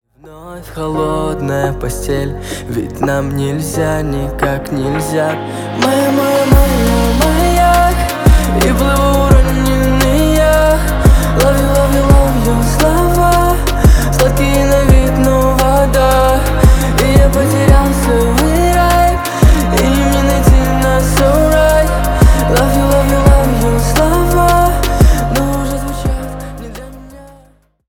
Поп Музыка
грустные
спокойные